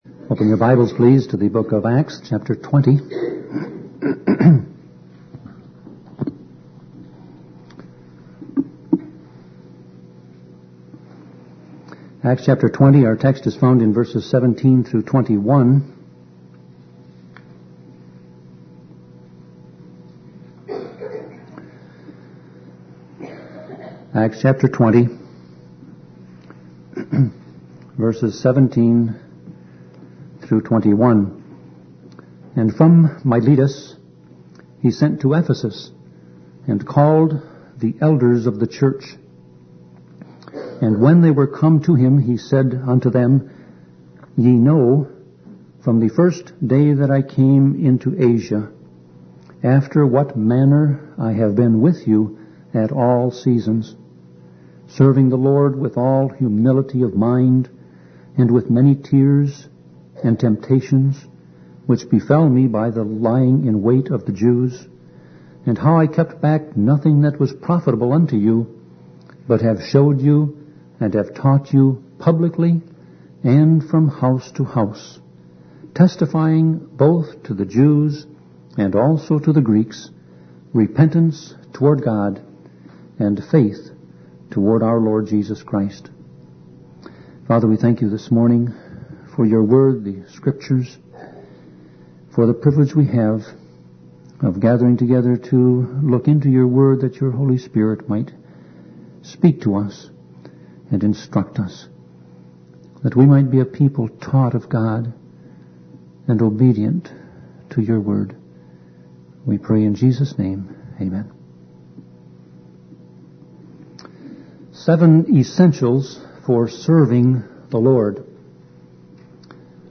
Sermon Audio Passage: Acts 20:17-21 Service Type